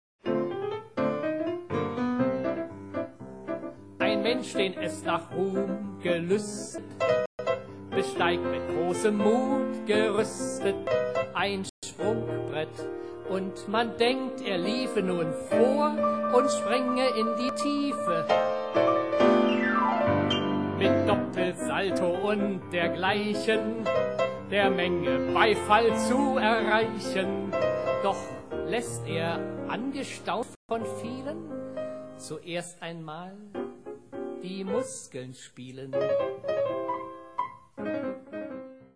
Heiteres Soloprogramm am Klavier